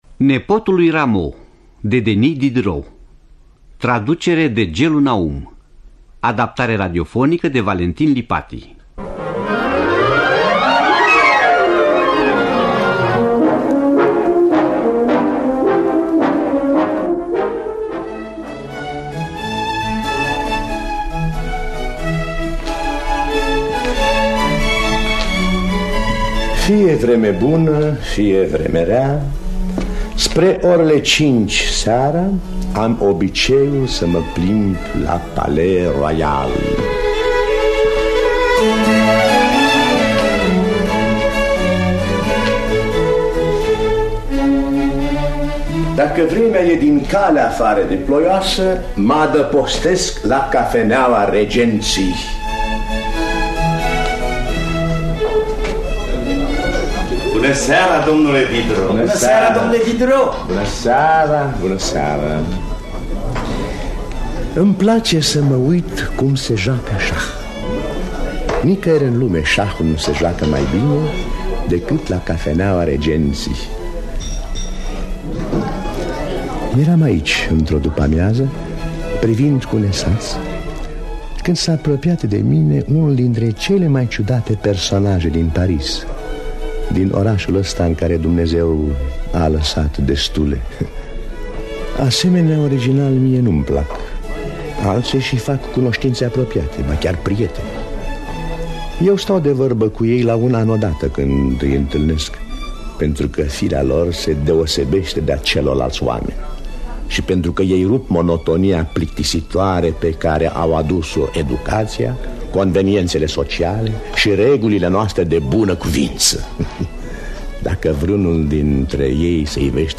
În distribuţie: Fory Etterle, Gheorghe Dinică, Coca Andronescu.